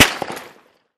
light_crack_04.ogg